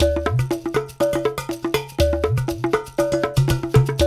120 -UDU 05R.wav